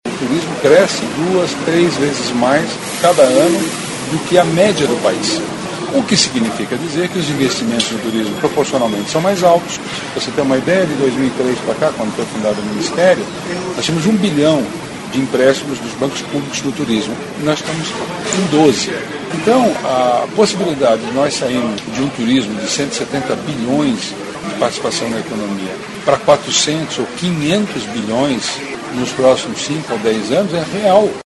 aqui para ouvir declaração do secretário Vinícius Lummertz sobre a importância do turismo à economia do país.